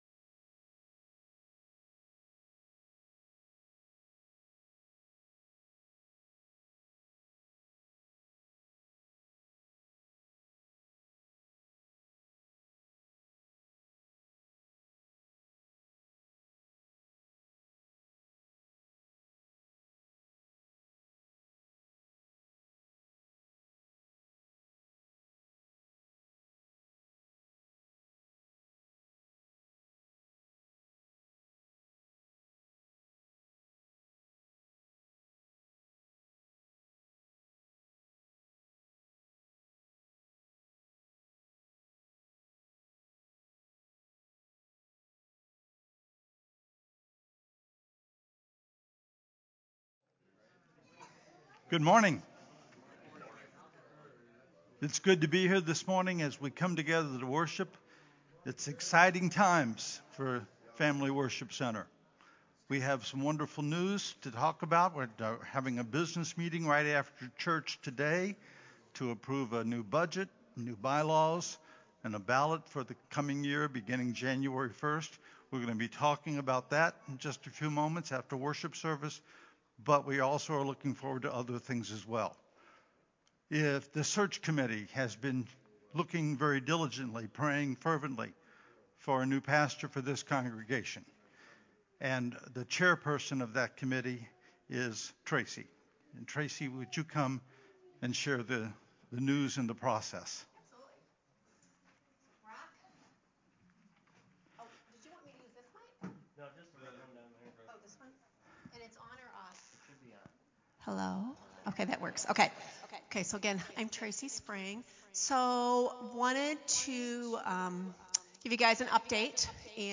“Just Love Me” Sermon
Just-Love-Me-Sermon-Audio-CD.mp3